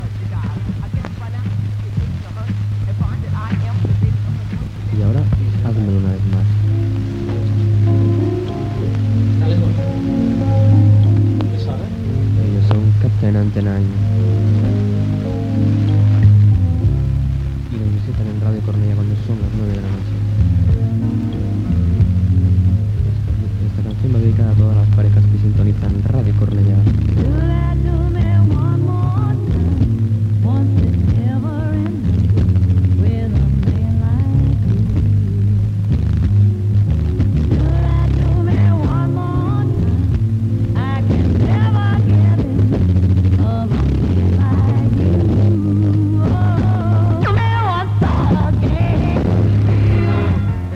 Identificació i tema musical